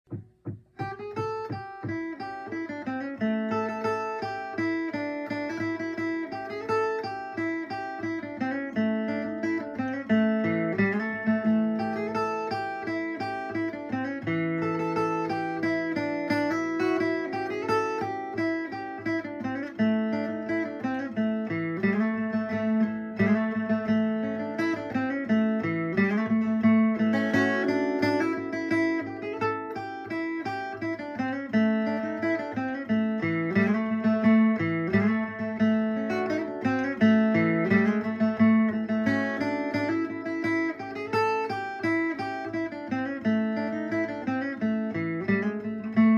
Key: Amix
Form: Reel
Region: Appalachian, Bluegrass